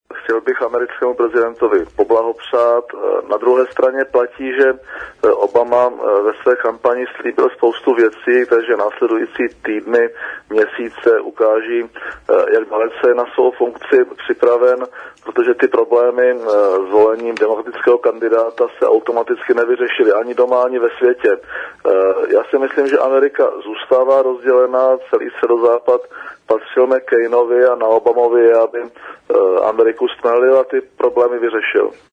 Vyjádření předsedy vlády ČR ke zvolení Baracka Obamy prezidentem USA pro ČRo Rádio Česko